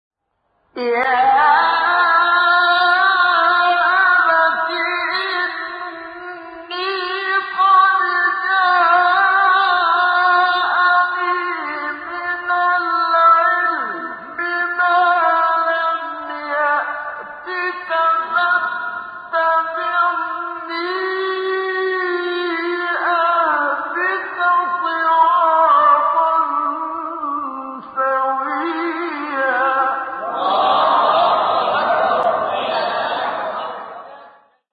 سوره : مریم آیه: 43 استاد : محمد صدیق منشاوی مقام : سه‌گاه قبلی بعدی